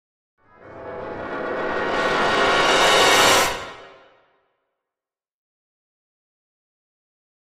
Brass Section, Tension Crescendo - "Danger Approach", Type 2